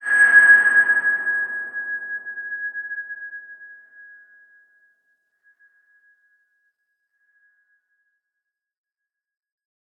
X_BasicBells-G#4-pp.wav